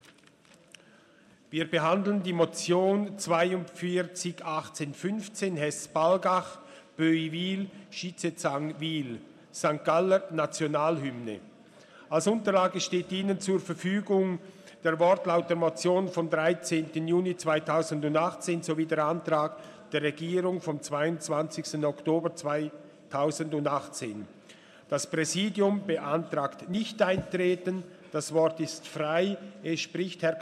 Session des Kantonsrates vom 18. und 19. Februar 2019